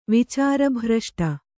♪ vicāra bhraṣṭa